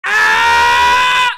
Звук крика кота Тома от боли в мультфильме Том и Джерри